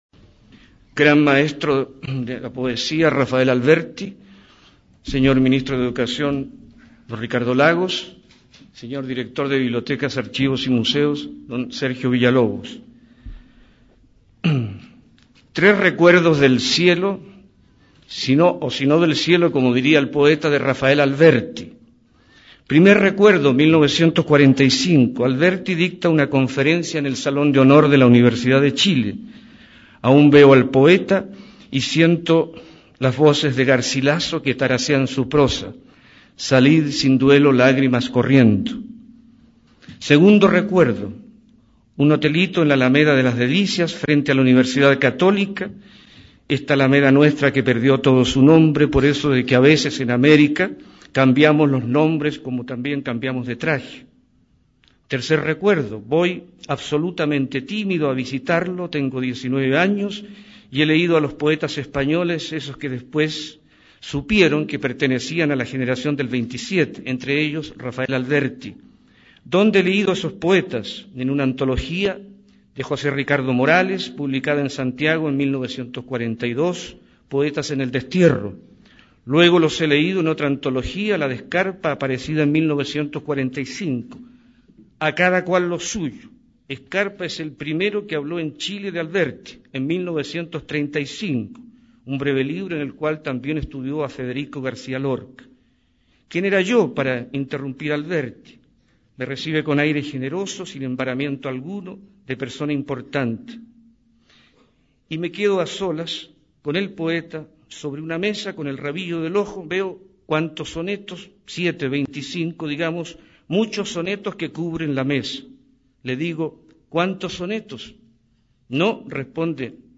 Miguel Arteche. Charla sobre la obra de Alberti
Aquí podrás escuchar un completo análisis de la obra de Rafael Alberti realizado por el poeta chileno Miguel Arteche. La conferencia corresponde a un homenaje al poeta andaluz, miembro de la Generación del 27, durante su visita a Chile el año 1991.